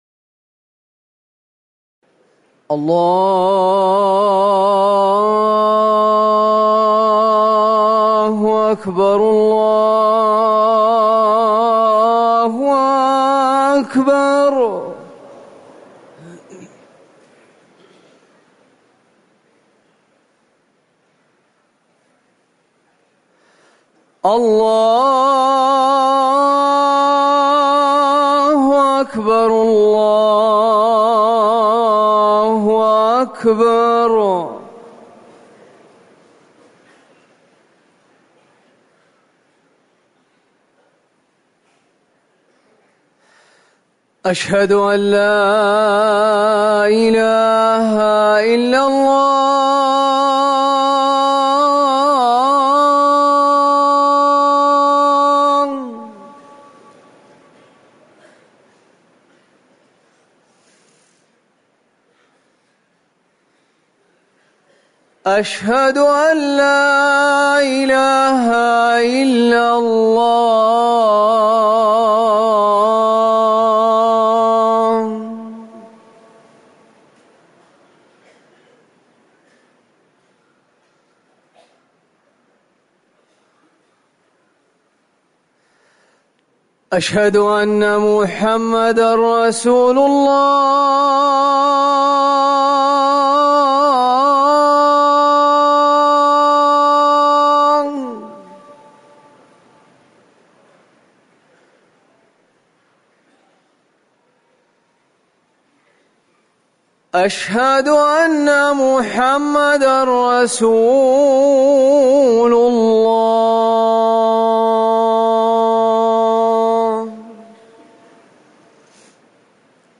أذان الفجر الثاني
تاريخ النشر ٣ محرم ١٤٤١ هـ المكان: المسجد النبوي الشيخ